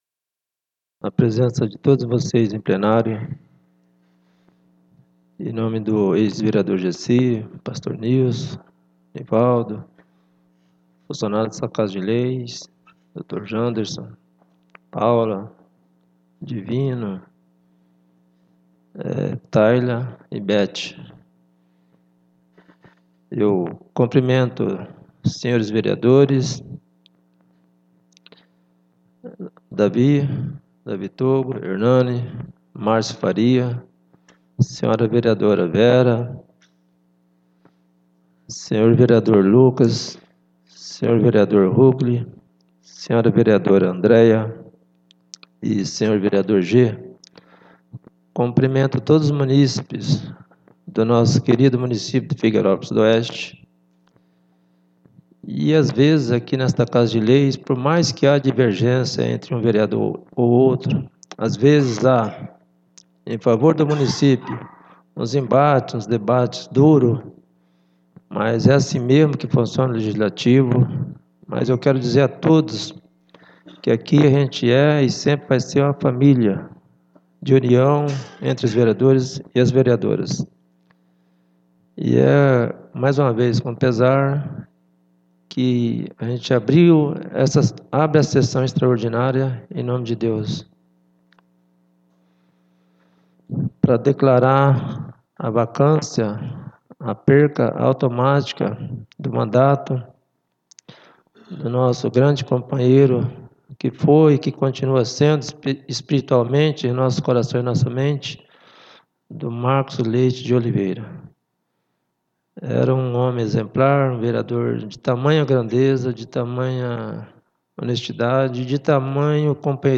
9° SESSÃO EXTRAORDINÁRIA DE 25 DE SETEMBRO DE 2025